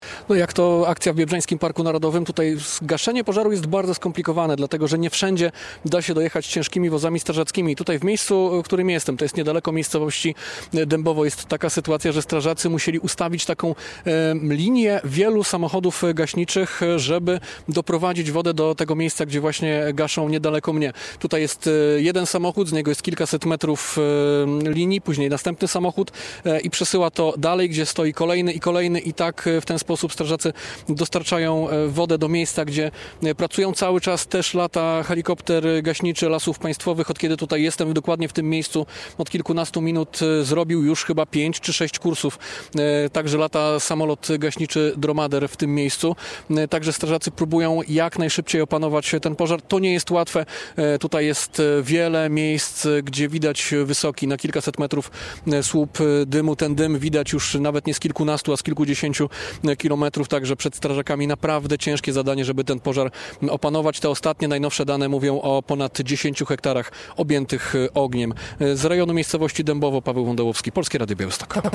Jak wygląda akcja gaśnicza w Biebrzańskim Parku Narodowym? - relacja